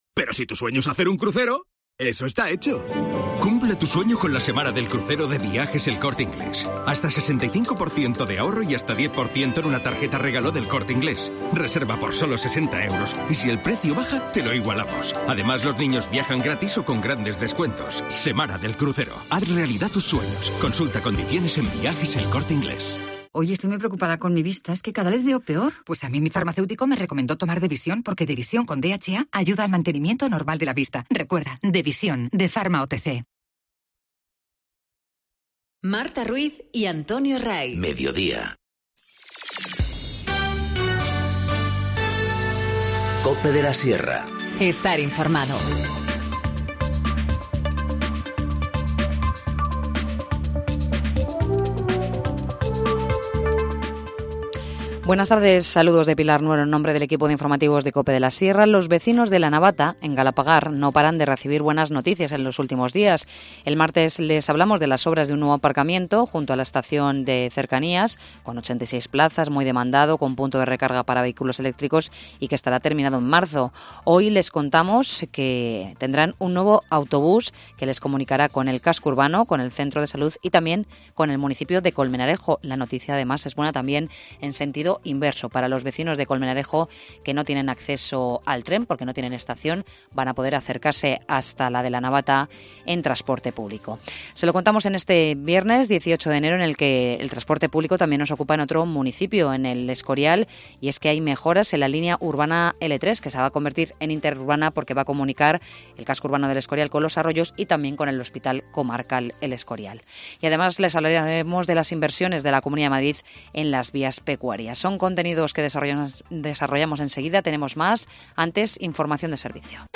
Informativo Mediodía 18 enero- 14:20h